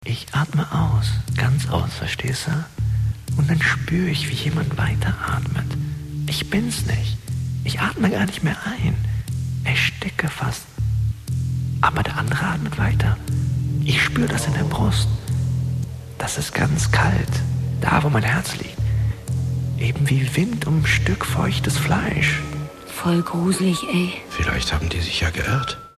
Sprecher türkisch für Werbung, Industrie, E-Learning, Imagefilme,
Sprechprobe: Werbung (Muttersprache):
turkish voice over artist